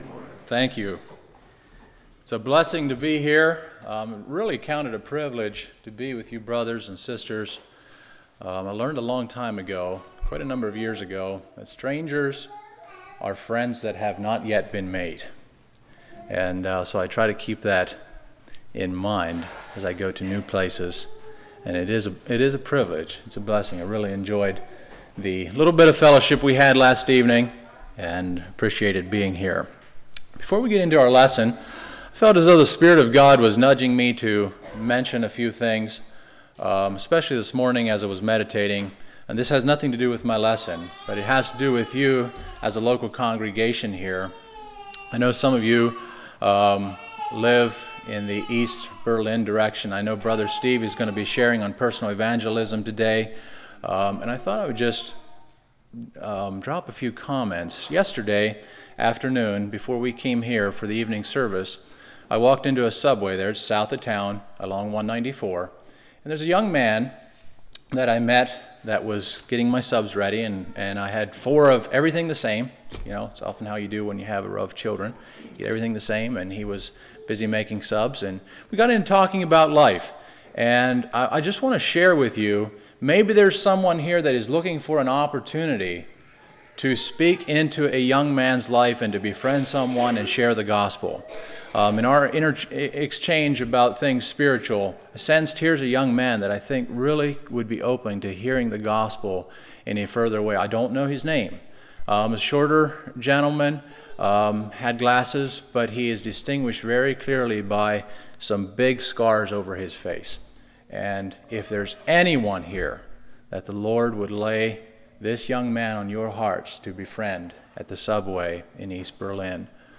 Passage: Isaiah 33:15-17 Service Type: Sunday School